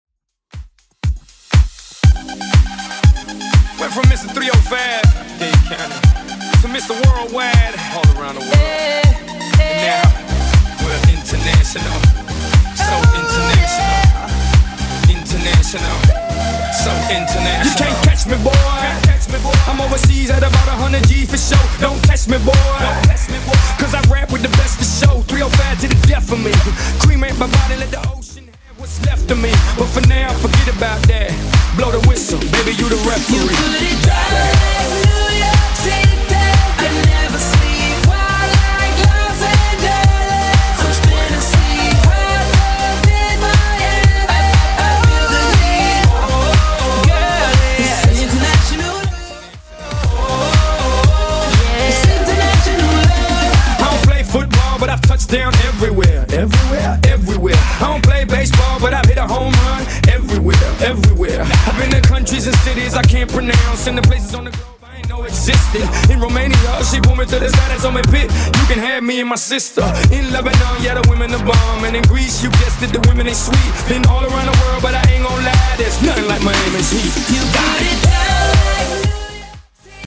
Genres: 70's , BOOTLEG
BPM: 124